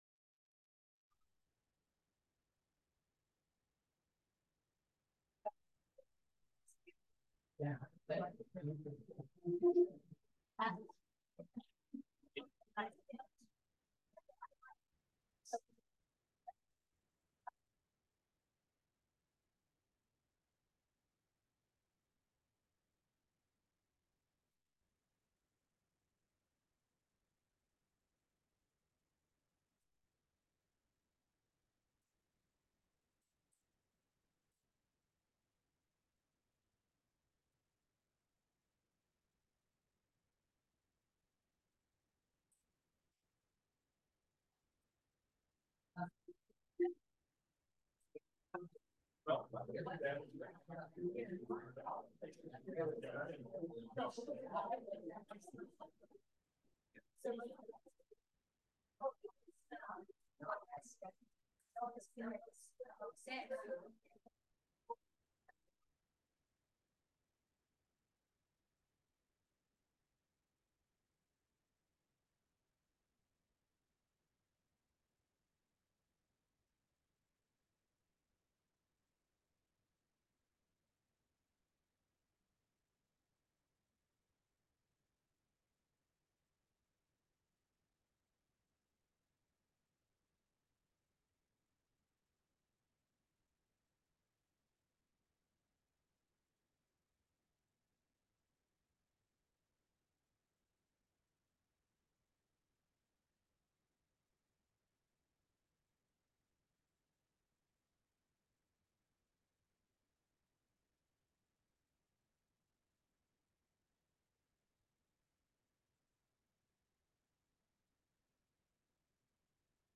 Eminent Scholar Lecture